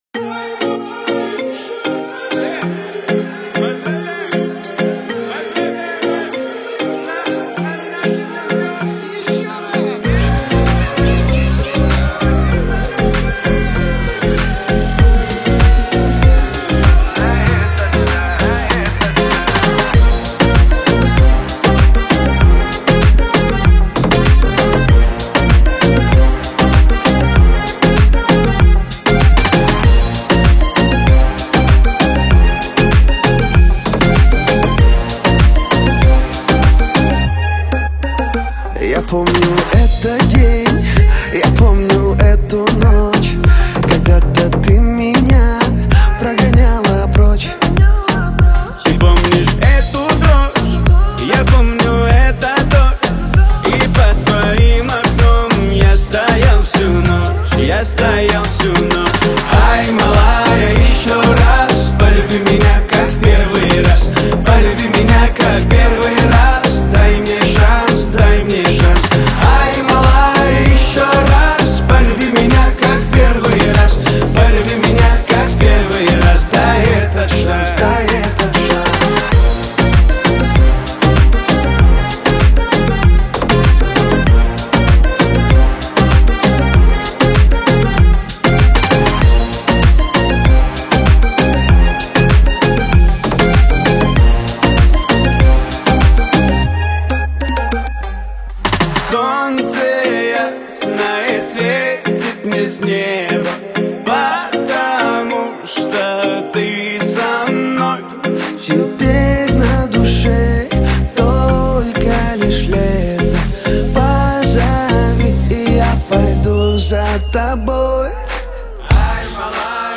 Танцевальная музыка
весёлые песни , dance песни